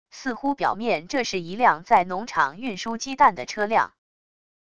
似乎表面这是一辆在农场运输鸡蛋的车辆wav音频